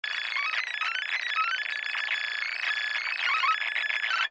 Звуки перемотки плёнки
Звук быстрой перемотки вперед